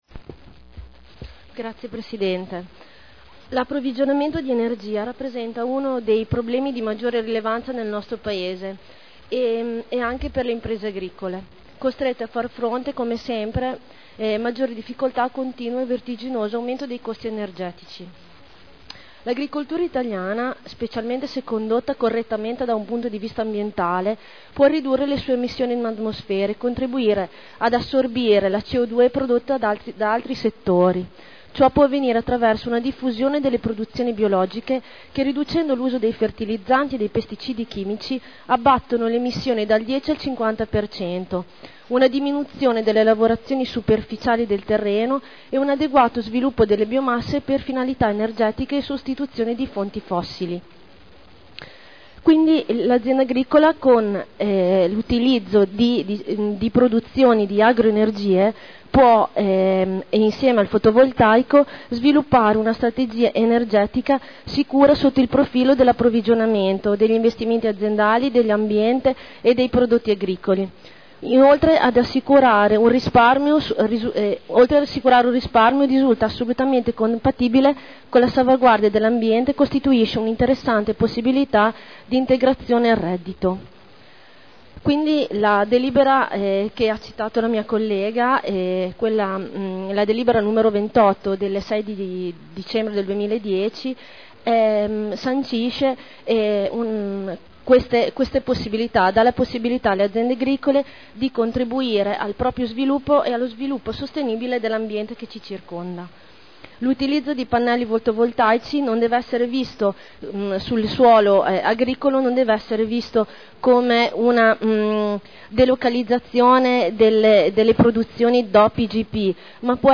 Seduta del 24/01/11. Dibattito su ordine del Giorno presentato in corso di seduta.